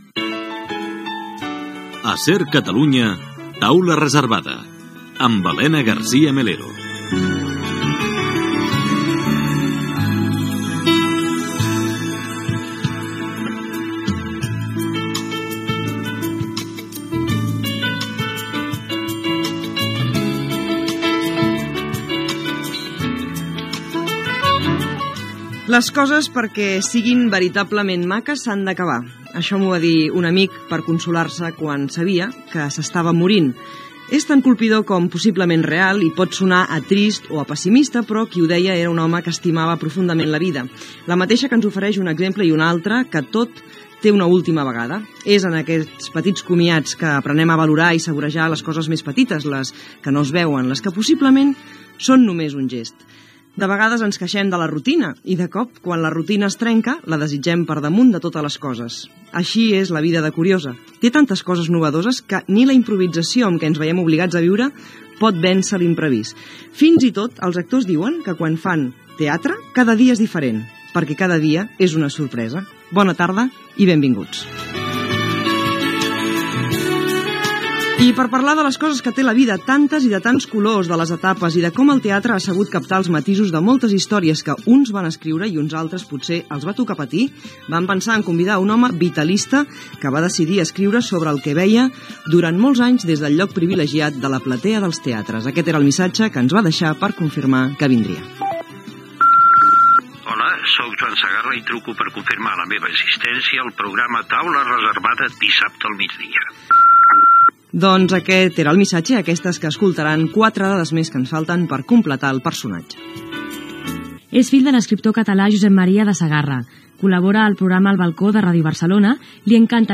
Primers minuts del programa amb Joan de Sagarra com a convidat. Presentació i qüestionari ràpid al convidat.
Entreteniment